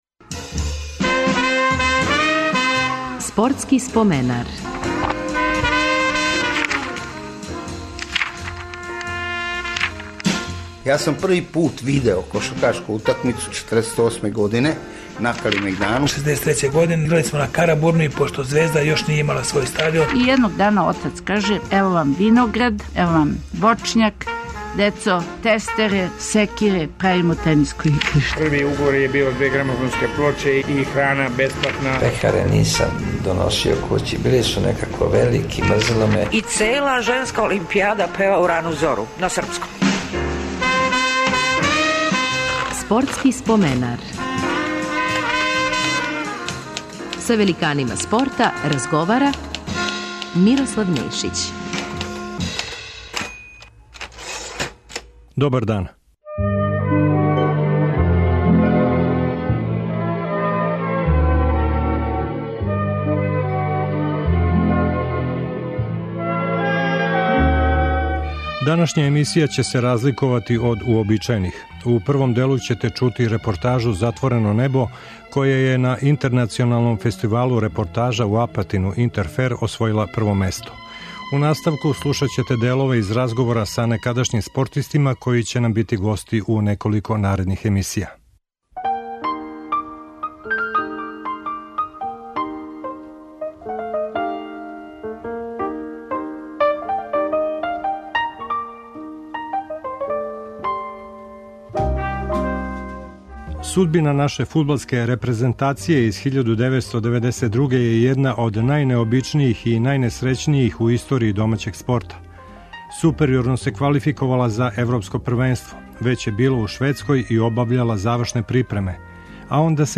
Коришћени су и архивски материјали са изјавама дотадашњег селектора Ивице Осима и капитена Драгана Стојковића. Слику драматичних збивања употпунили су и звучни ефекти.